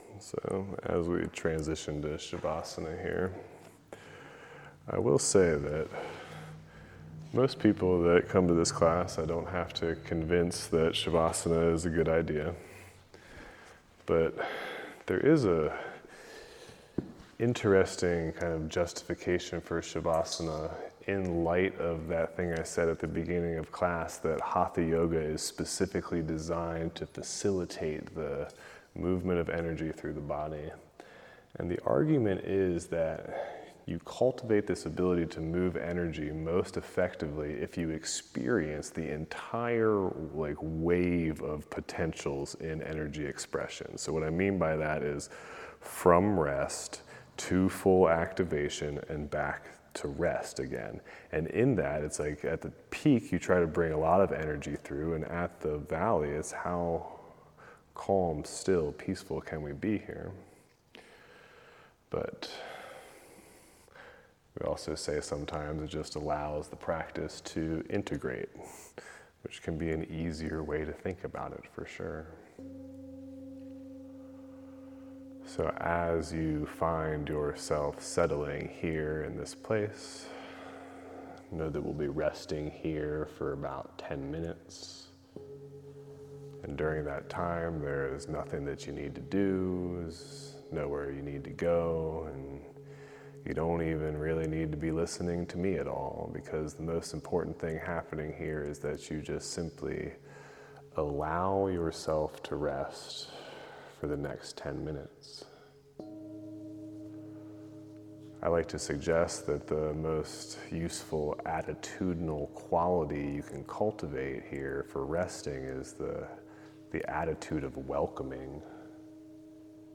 The addition of my set of brass singing bowls in summer 2024 has become a beloved mainstay. Recorded using a wireless lapel microphone. Theming/Inspiration > Settling > Body Sensing > Sounds > Om and Closing
Recorded live the evening of the Fall Equinox 2025.
Download Donate 09/22/25 07:15 PM (15 minutes) Live @ Arcata Presbyterian Church During Monday Night Hatha Recorded live the evening of the Fall Equinox 2025.